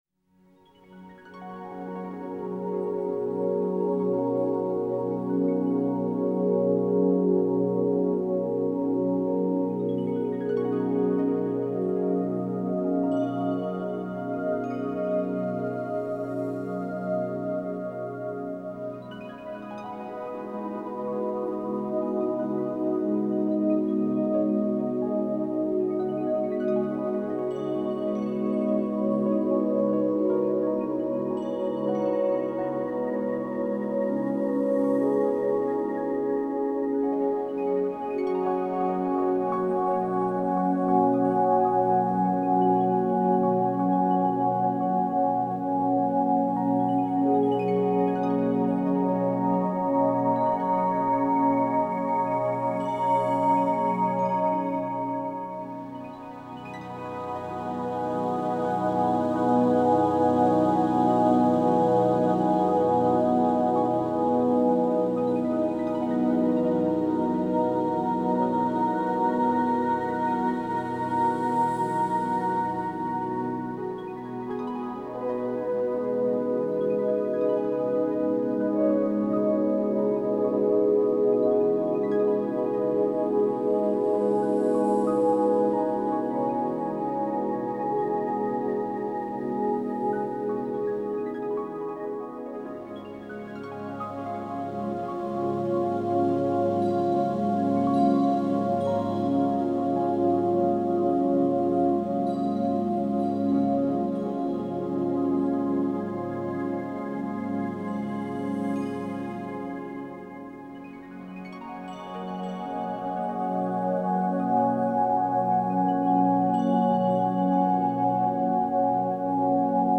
Znova hudba vhodná pre reiki, relaxáciu a meditáciu.